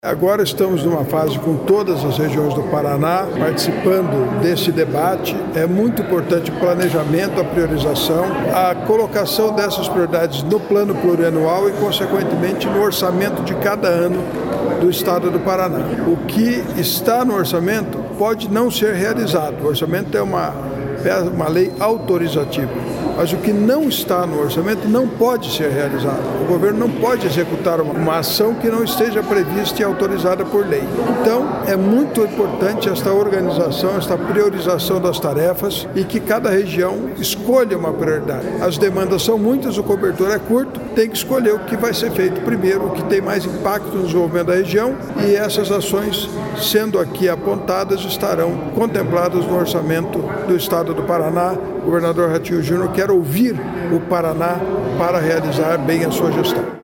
Sonora do secretário de Indústria, Comércio e Serviços, Ricardo Barros, sobre a instalação do Conselho Gestor do Paraná Produtivo